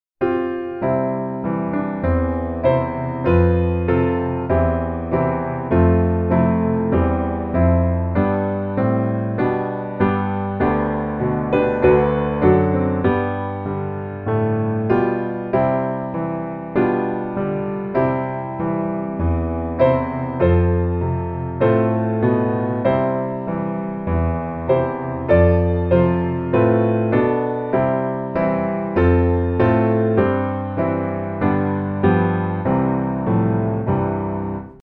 C大调